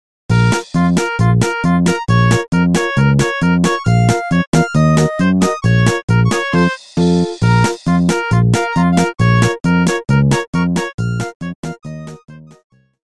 Nokia полифония. Народные